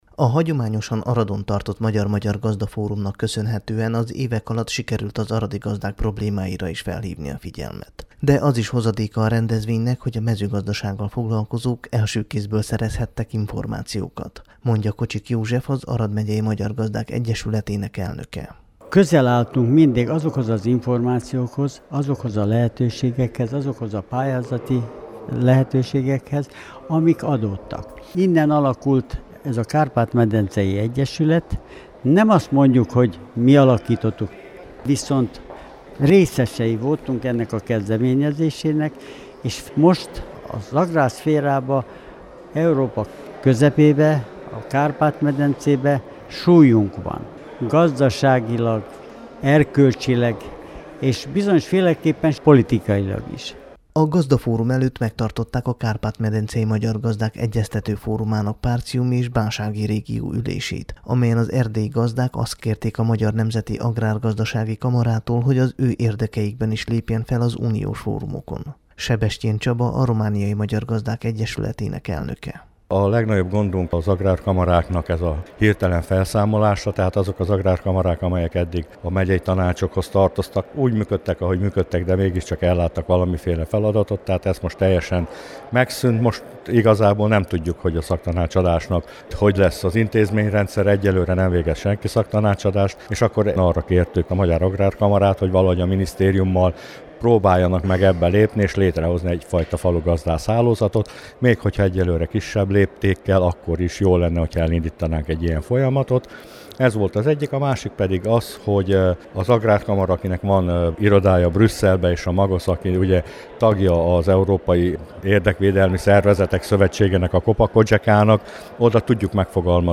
Az aradi Agromalim nemzetközi mezőgazdasági vásár keretében megtartották a 17. találkozót a magyarországi és a romániai magyar gazdaszervezetek képviselői között. Az Agrárium jelene és jövője címmel szervezett fórumon a támogatások késése okozta bonyodalmak mellett arról is szó esett, hogy a romániai gazdáknak új perspektivákat nyithat a szövetkezeti törvény módosítása, illetve az a szabályozás, amely arra kényszeríti a nagy áruházlánockat, hogy az élelmiszeripari termékek több mint felét úgynevezett rövid értékesítési láncon szerezzék be, azaz a helyi áruk polcra kerülését szorgalmazza.